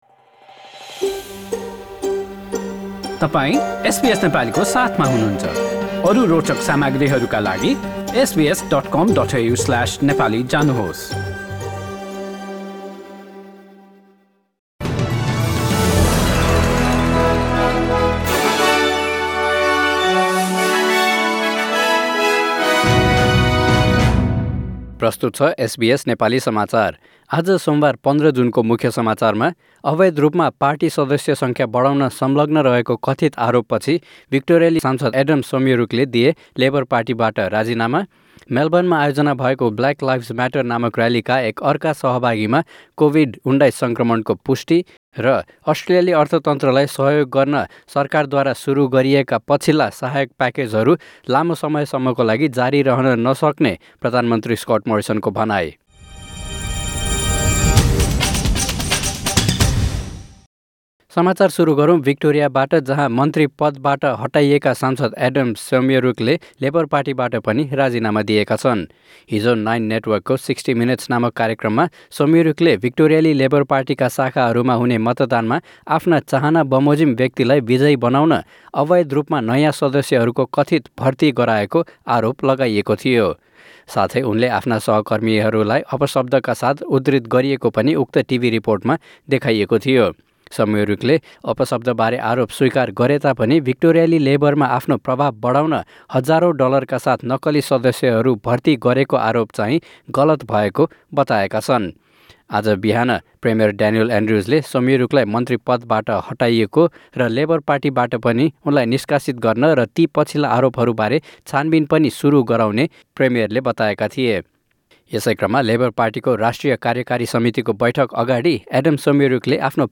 एसबीएस नेपाली अस्ट्रेलिया समाचार: सोमवार १५ जुन २०२०